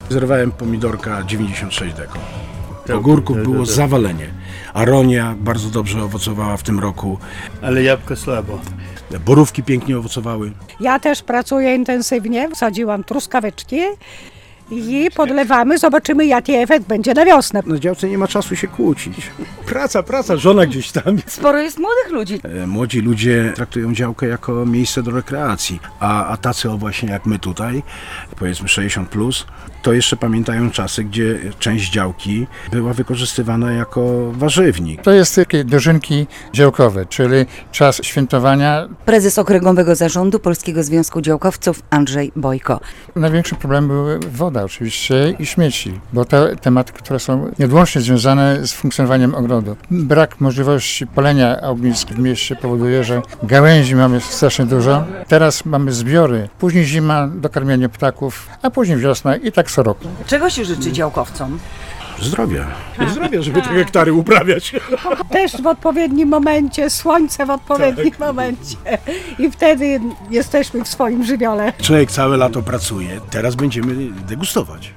W sobotę (9.09) w ogrodzie przy ul. Ciołkowskiego w Białymstoku obchodzili Okręgowe Dni Działkowca.